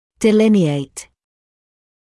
[dɪ’lɪnɪeɪt][ди’линиэйт]очерчивать, обрисовывать, изображать;